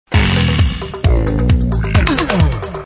Звуки мужского голоса